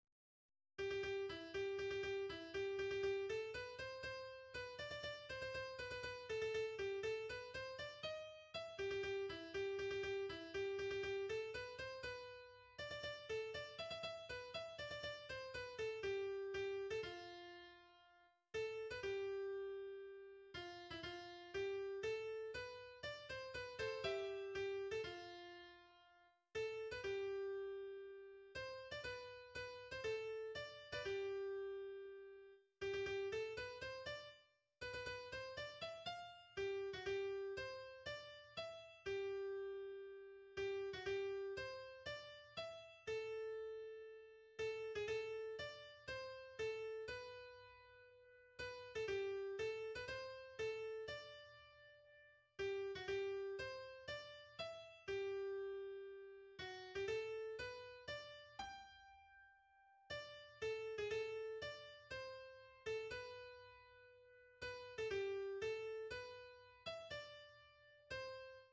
danse : polka
Recherches pour l'enseignement de la musique traditionnelle